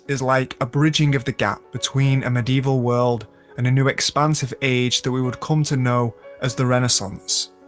Vocal Sample